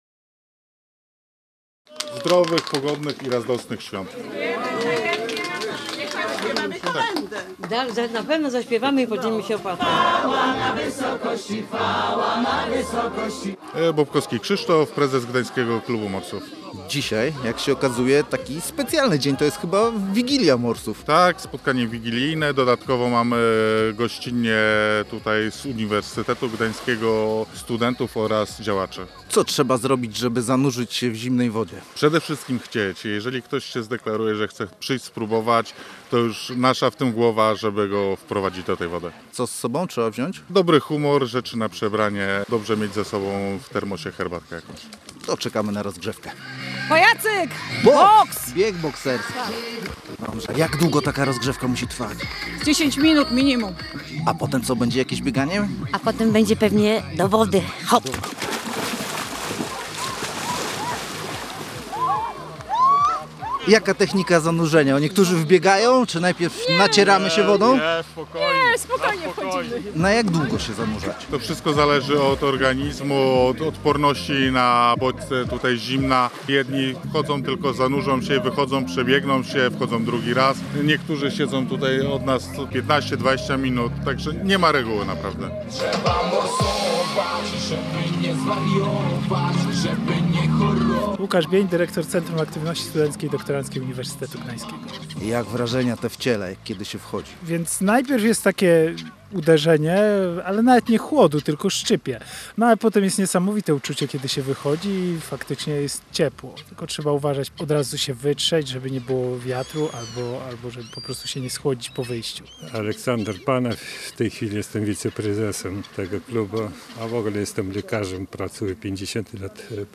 Gdański Klub Morsów, który działa już od pół wieku, zaprosił swoich członków i sympatyków na wyjątkową wigilię, ale nie przy stole, a w wodzie. Był opłatek, były kolędy, a zaraz potem szybkie „ho, ho, ho!” i wspólna kąpiel w lodowatym Bałtyku.